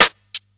• Old School Wet Rap Acoustic Snare G Key 142.wav
Royality free snare drum sound tuned to the G note. Loudest frequency: 2567Hz
old-school-wet-rap-acoustic-snare-g-key-142-9dX.wav